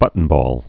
(bŭtn-bôl)